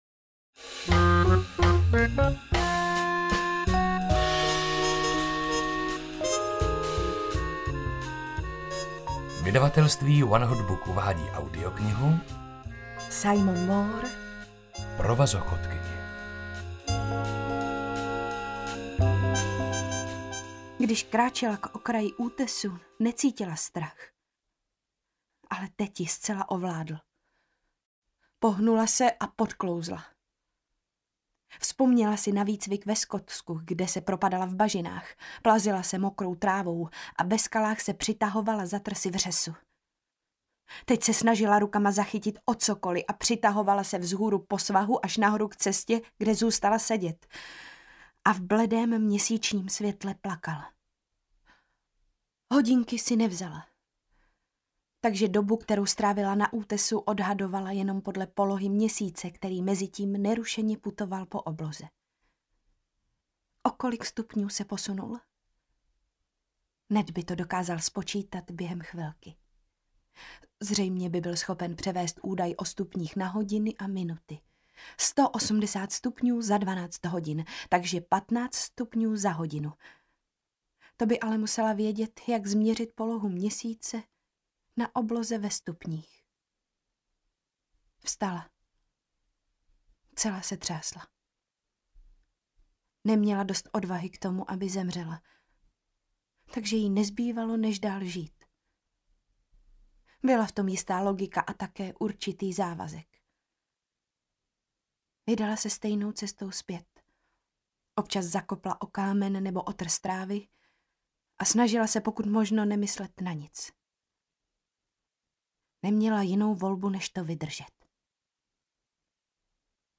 Provazochodkyně audiokniha
Ukázka z knihy